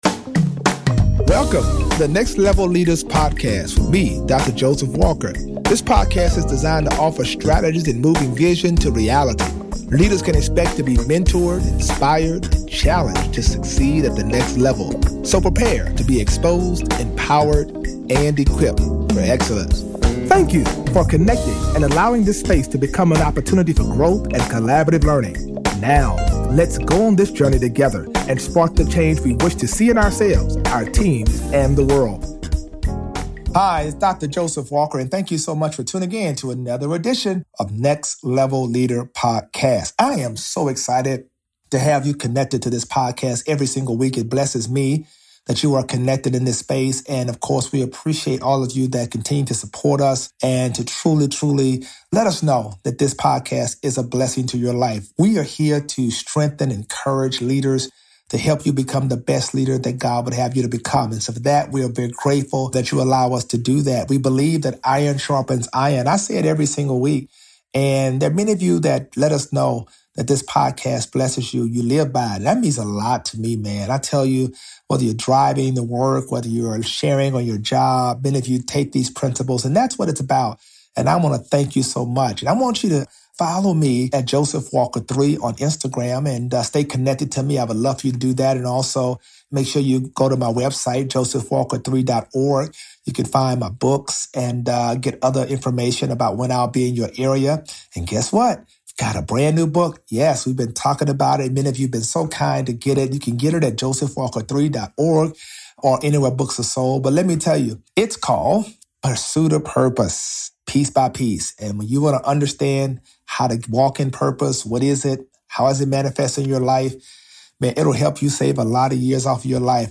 Each episode addresses the intersect between Christianity and the marketplace through conversations with successful leaders. Listeners will be mentored, inspired, and challenged to succeed at the next level.